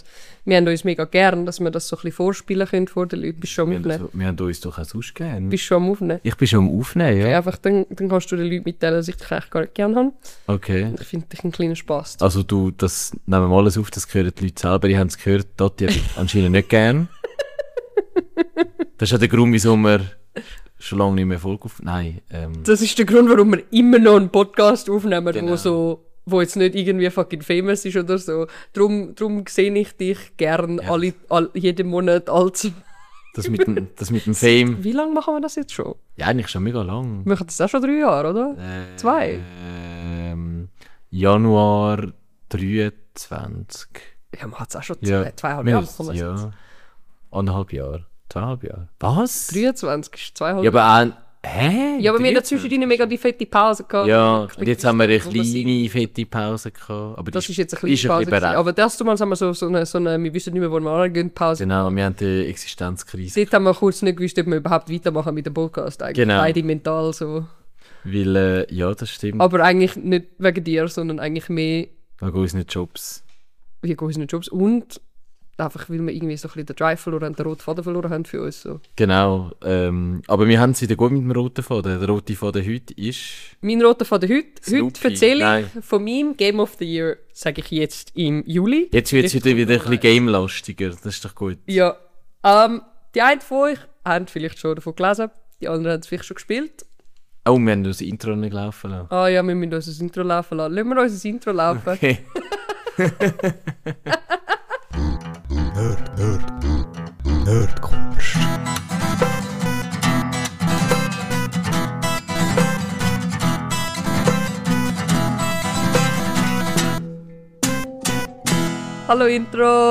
Zwei Hosts, zwei Lieblingsthemen, jede Menge Leidenschaft – mitreißend, unterhaltsam, sympathisch.